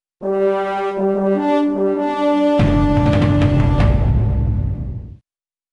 管乐.wav
管乐.wav 0:00.00 0:05.74 管乐.wav WAV · 988 KB · 立體聲 (2ch) 下载文件 本站所有音效均采用 CC0 授权 ，可免费用于商业与个人项目，无需署名。
乐器类/重大事件短旋律－宏大/管乐.wav